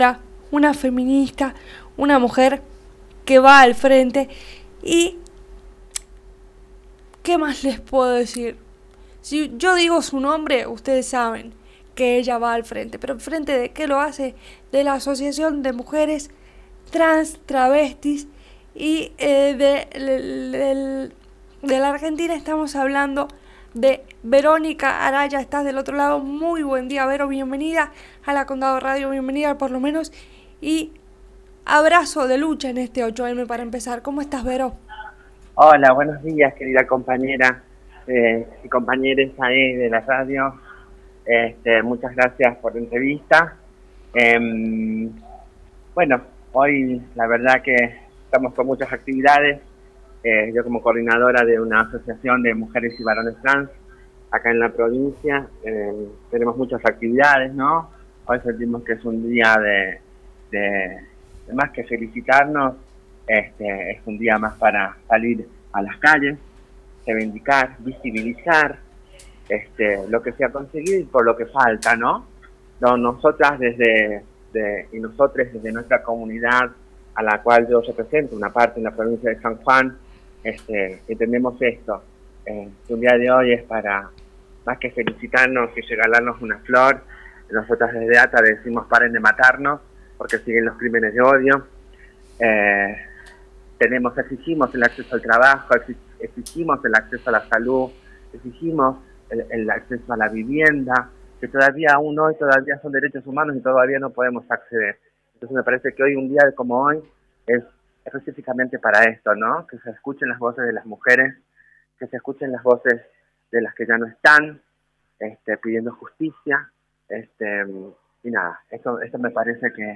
Desde la «Condado Radio» se preparo un programacion especial para acompañar la lucha de las mujeres y diversidades.